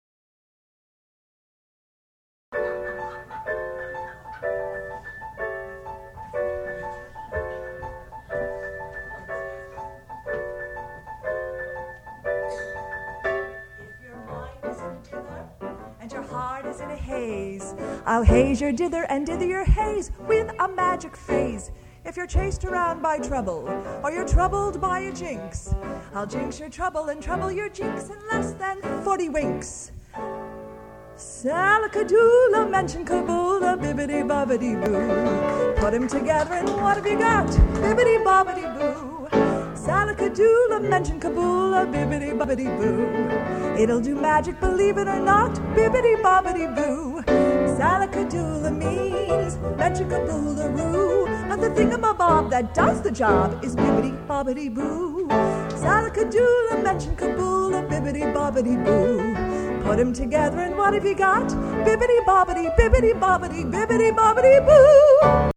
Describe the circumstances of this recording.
The following songs were recorded live on March 21